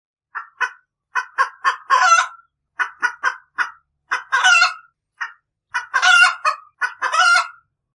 Chicken Sound Button - Free Download & Play